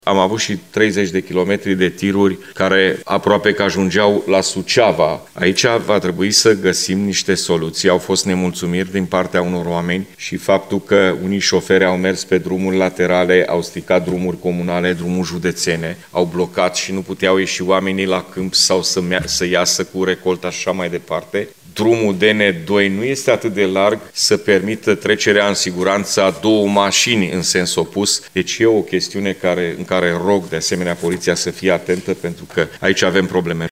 În cadrul ședinței legislativului județean, el a spus că trebuie găsite soluţii pentru rezolvarea problemei.